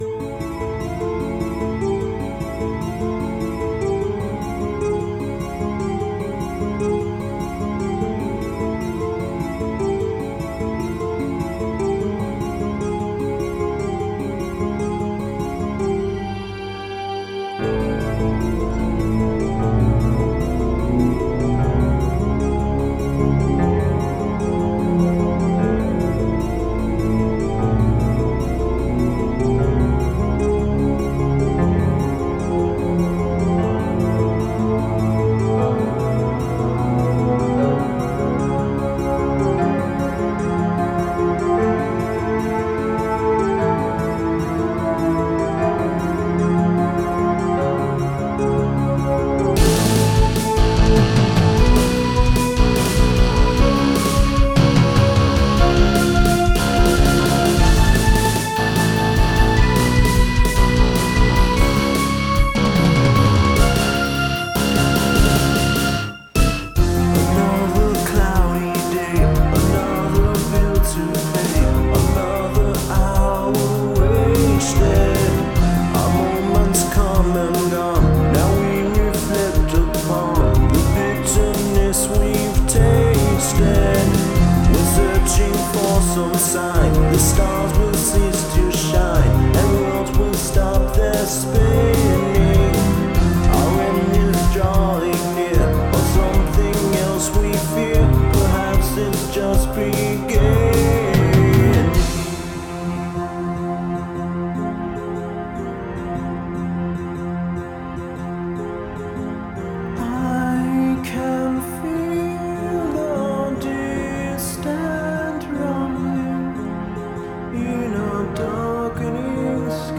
The song is complex but it flows well. Rather than jarring the listener from one section to another, it just sort of rolls along, despite having a lot of parts.
Unfortunately, I've never gotten it to sound as good as it is in my head for some reason.